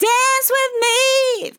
Tags: Dance, DISCO VIBES, dry, english, female, LYRICS, me, sample, with
POLI-LYRICS-Fills-120bpm-Fm-1.wav